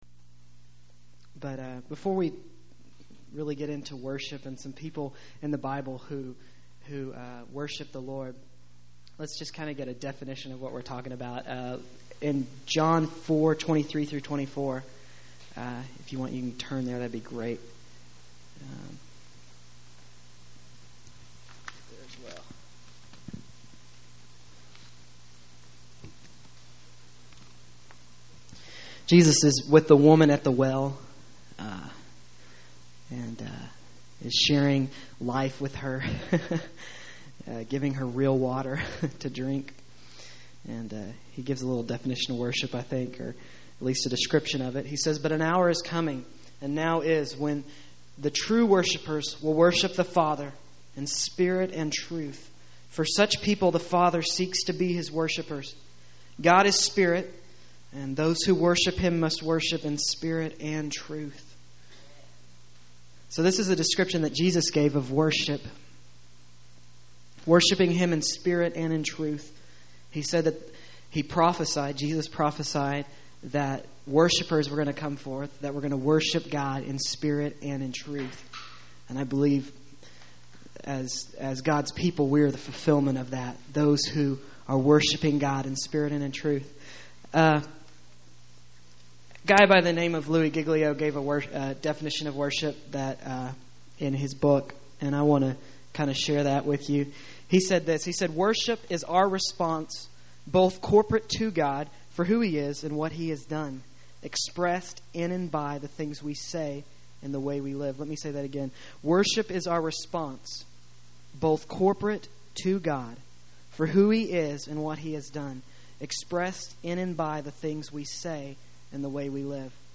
Extravagant Worship Teaching